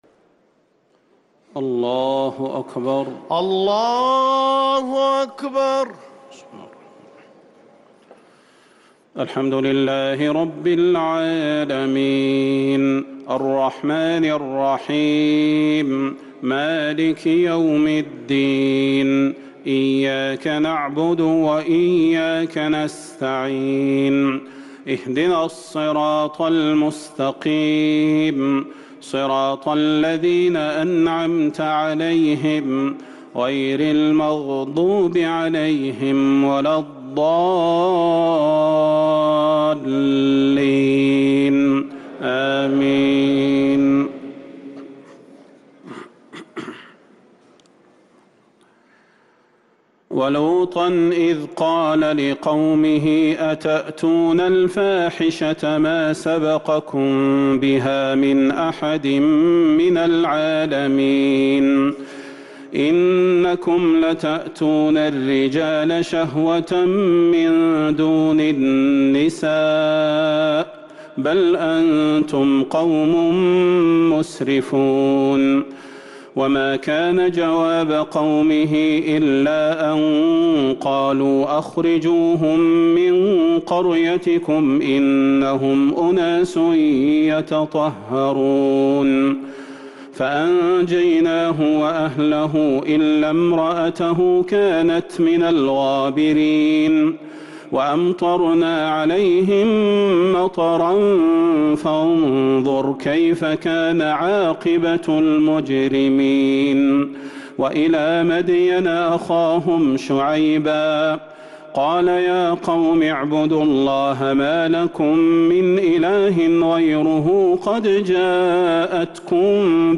تراويح ليلة 11 رمضان 1444هـ من سورة الأعراف (80-141) | taraweeh 11st niqht ramadan Surah Al-A’raf 1444H > تراويح الحرم النبوي عام 1444 🕌 > التراويح - تلاوات الحرمين